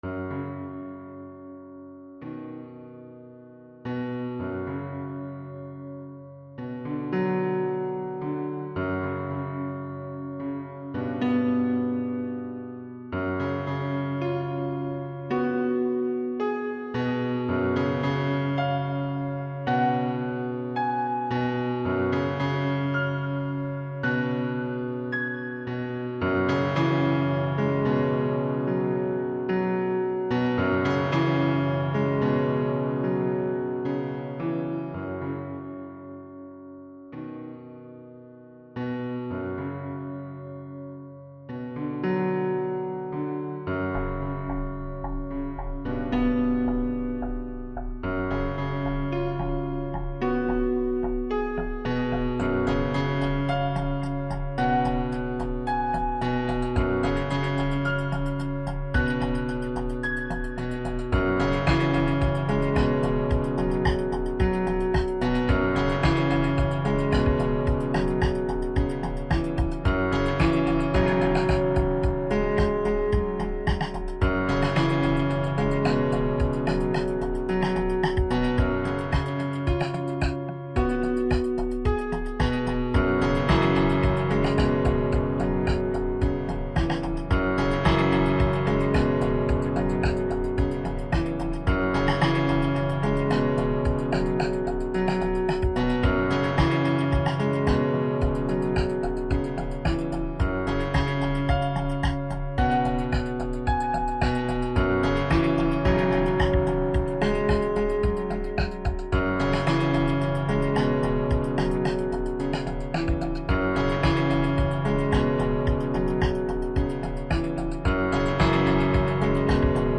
rhythmisches Klavierstück (2,7 MB)